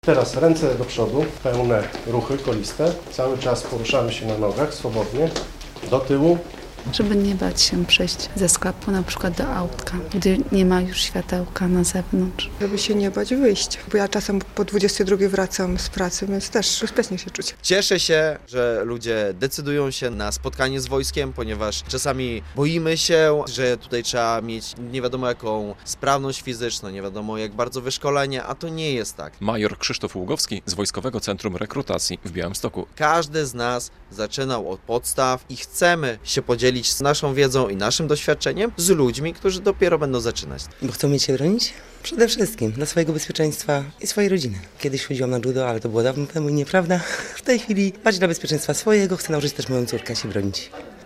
W białostockim Muzeum Wojska zorganizowano zajęcia z samoobrony - relacja